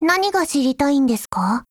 贡献 ） 协议：Copyright，其他分类： 分类:少女前线:史蒂文斯520 、 分类:语音 您不可以覆盖此文件。